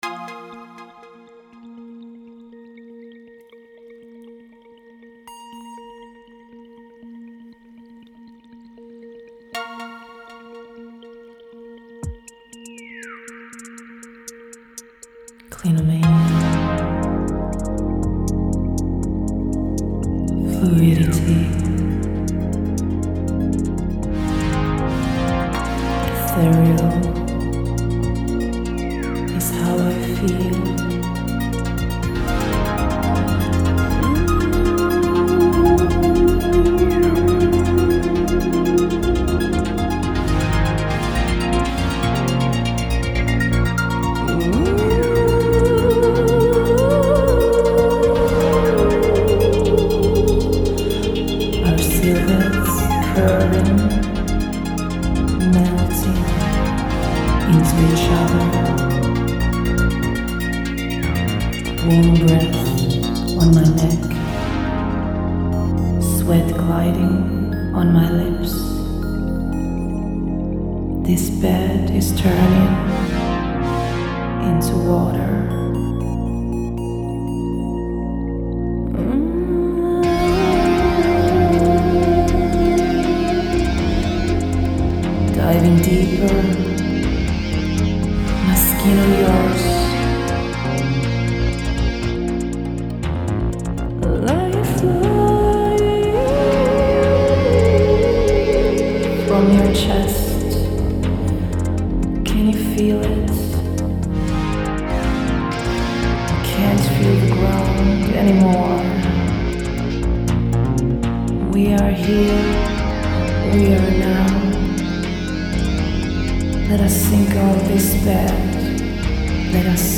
Kompo Vikkelä musiikki
quick joint with Rambo-inspired chords
Logic Pro with Scaler 2, Spire, Surge XT, Valhalla Supermassive